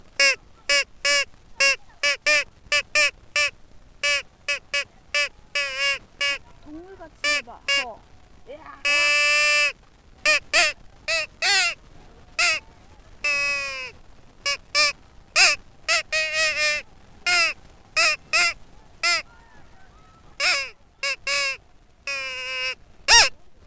까마귀피리.mp3